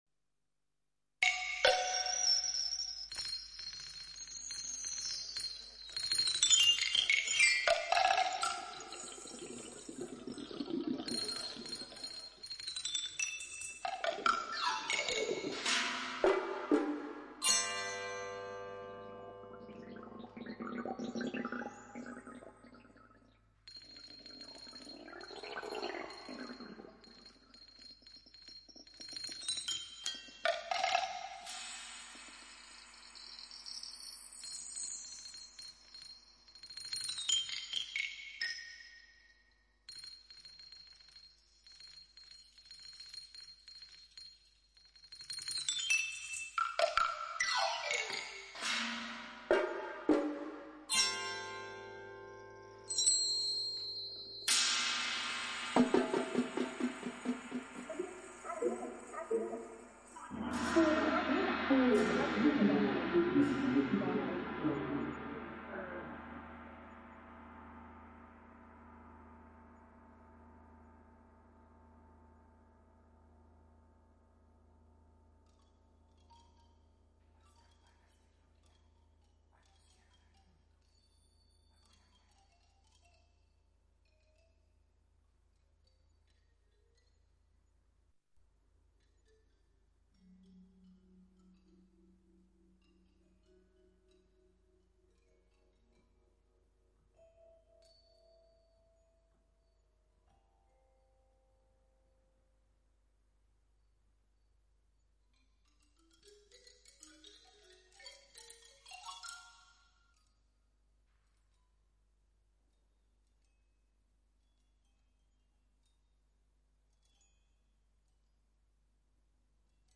for percussion and computer (1991)